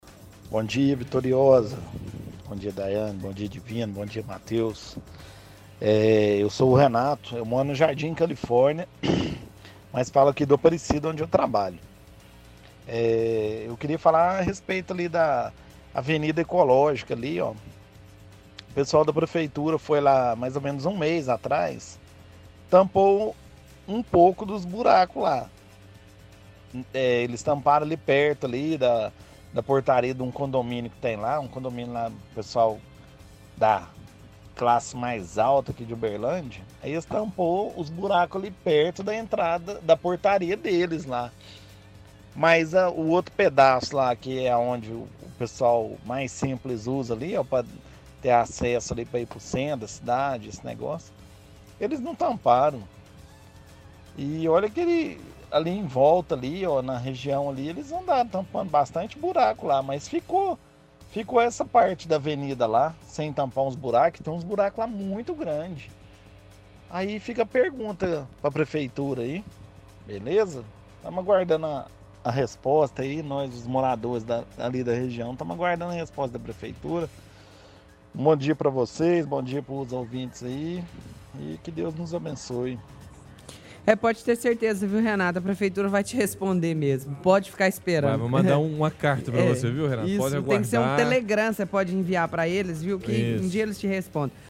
– Ouvinte diz que prefeitura fez trabalho de tapar buracos, mas deixou vários sem manutenção.